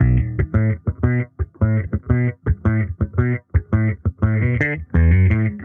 Index of /musicradar/sampled-funk-soul-samples/85bpm/Bass
SSF_JBassProc1_85B.wav